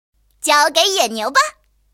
野牛编入语音.OGG